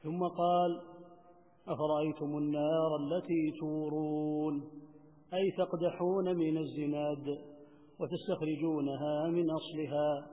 التفسير الصوتي [الواقعة / 71]